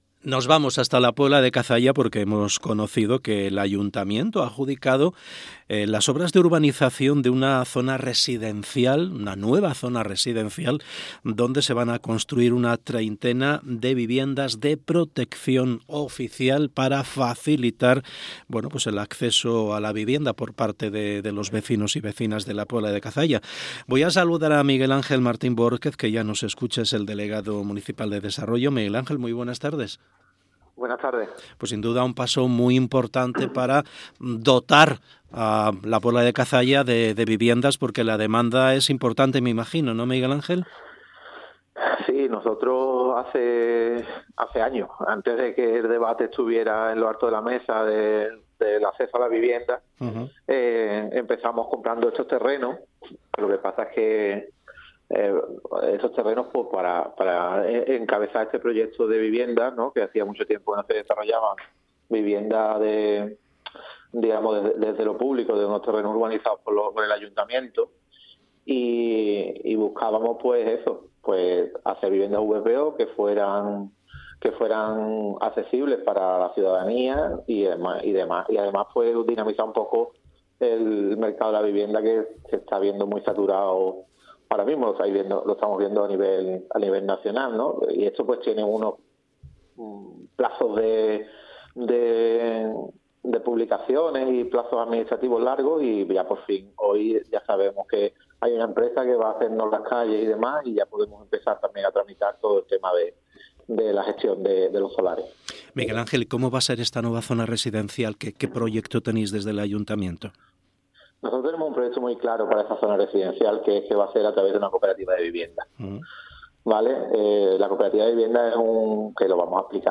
ENTREVISTA MIGUEL ANGEL MARTÍN BOHORQUEZ - Andalucía Centro